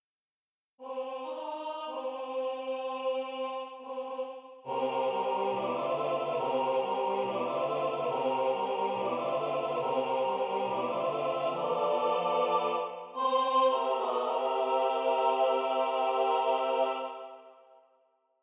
How many parts: 4
Type: SATB
All Parts mix: